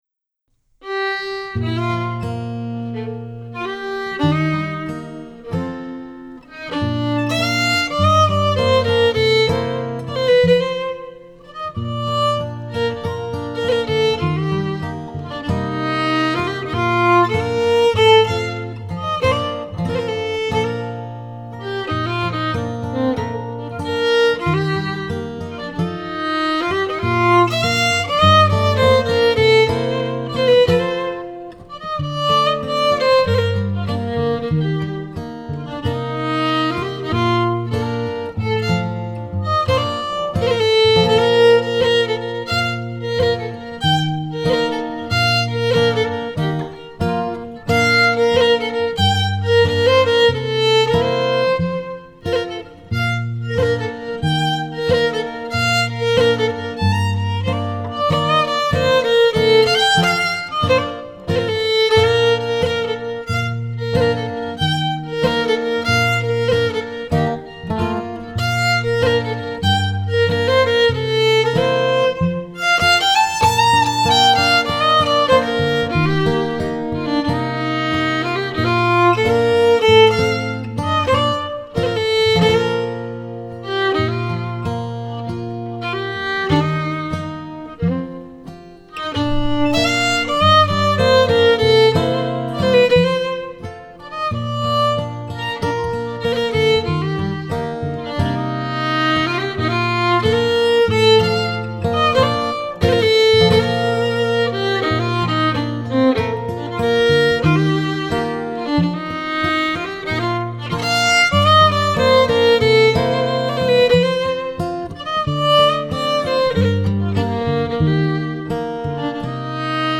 Scottish and Québécois fiddling.
à la guitare
au piano
aux percussions